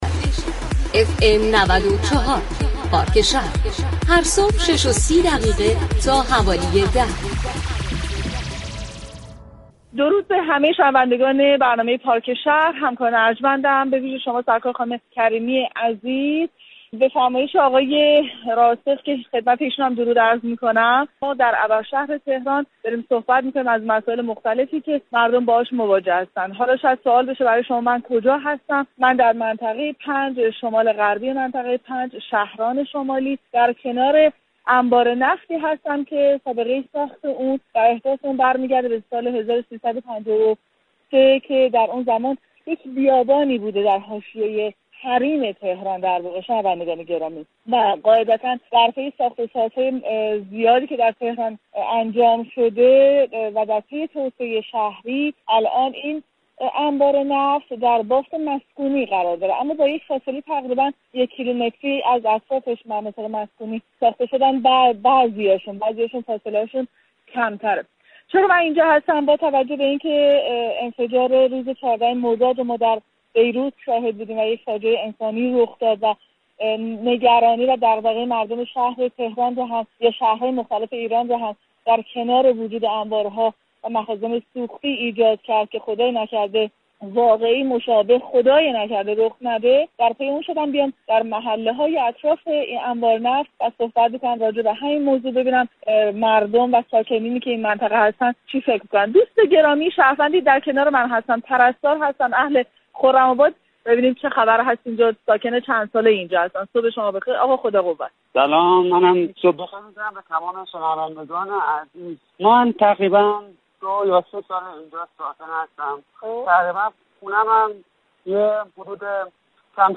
رئیس سازمان پیشگیری و مدیریت بحران شهر تهران در گفتگو با پارك شهر گفت: ما در شهرداری و سازمان مدیریت بحران در حال پیگیری بازبینی و طراحی مجدد انبار نفت شهران هستیم اما اجرای آن برعهده دو وزارتخانه نفت و كشور است.
در همین راستا برنامه پارك شهر 19 مردادماه با دكتر رضا كرمی محمدی رئیس سازمان پیشگیری و مدیریت بحران شهر تهران گفتگو كرد.